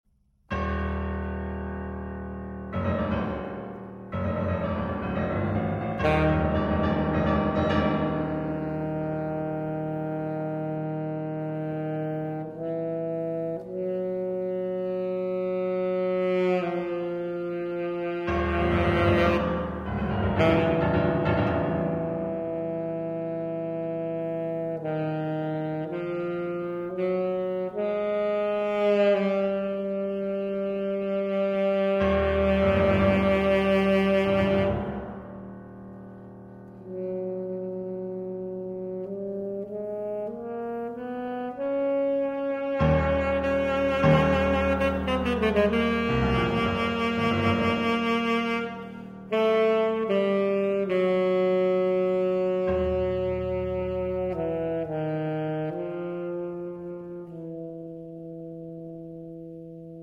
saxophone
piano